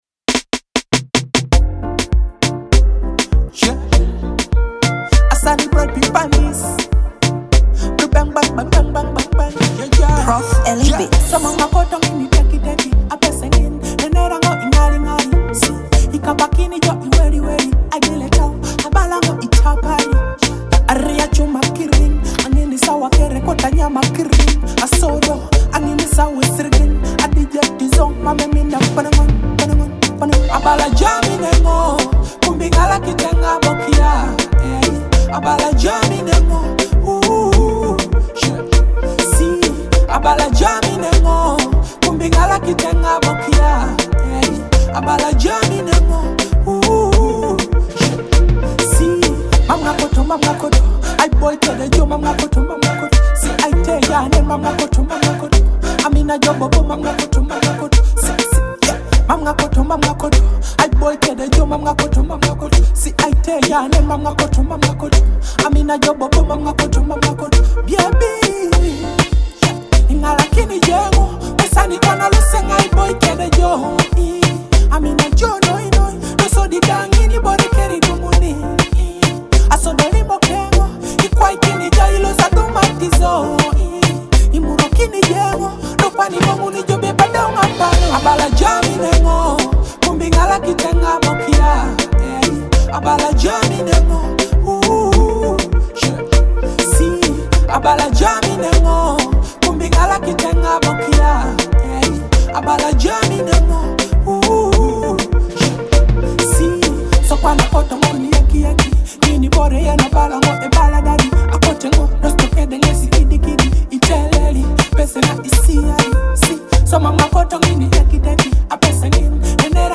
Driven by vibrant dancehall beats and relatable storytelling